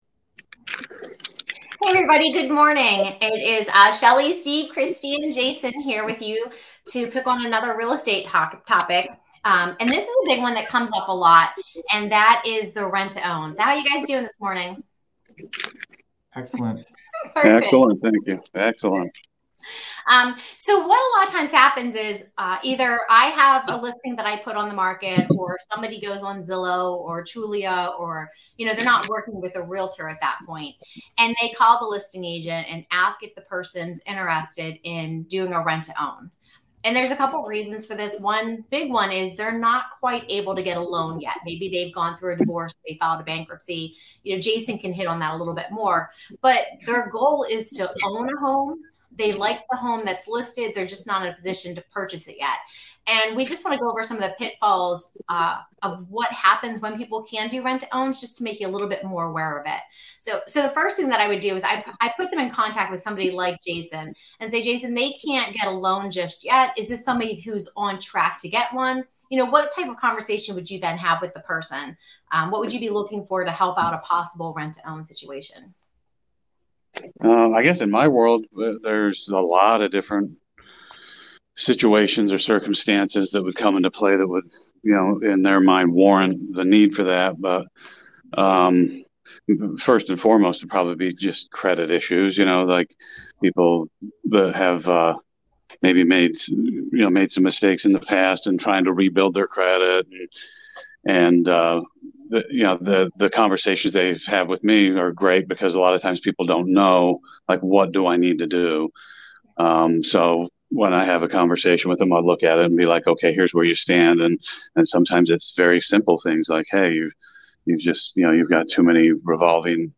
Today our mastermind group discusses rent to own. It can be a great solution for some people, but the public should be aware of the pitfalls to both the buyer/tenant and seller/landlord.